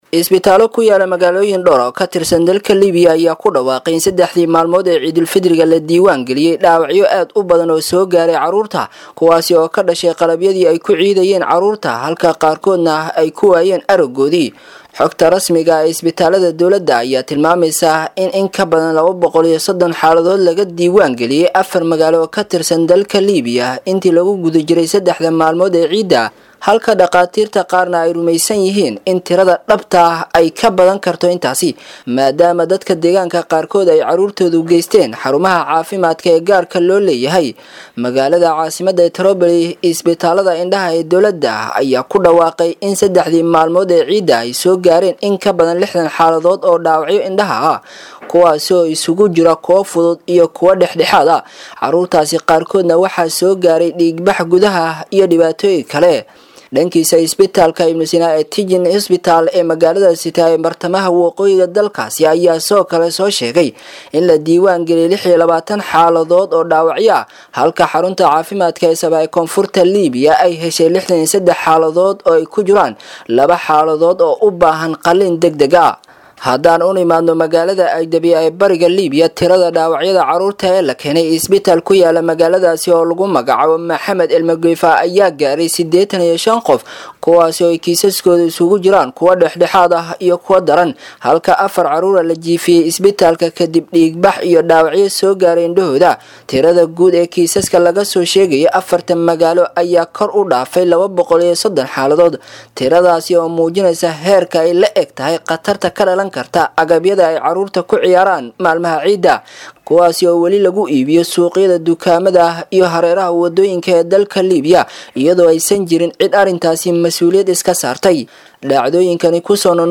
Reer Liibiya oo ay Waxyeello Kala Duwan Kasoo Gaartay Qalabka ay Caruurtu ku Ciidaan.[WARBIXIN]
Halkaan-ka-Degso-Warbixinta-Liibiya.mp3